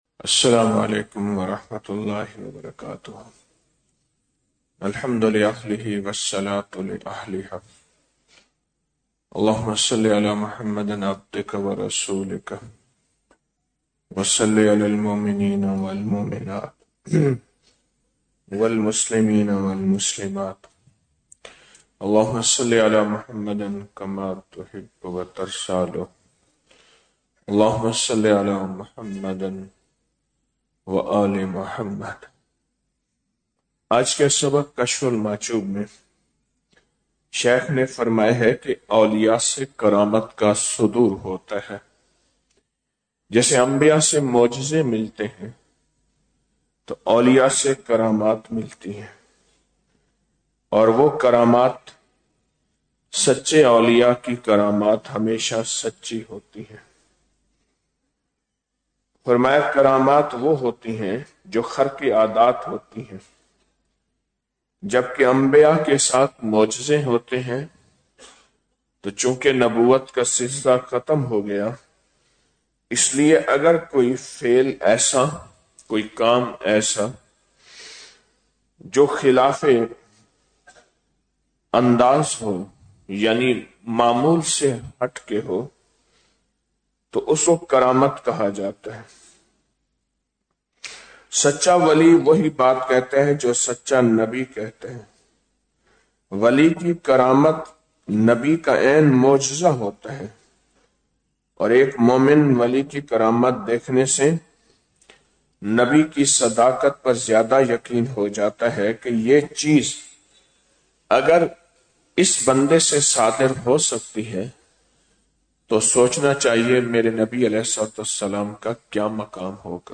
روحانی نسبت کو بچانے اور بڑھانے کا راز -12 رمضان المبارک بعد نماز فجر- 02 مارچ 2026ء